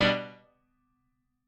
admin-leaf-alice-in-misanthrope/piano34_6_005.ogg at main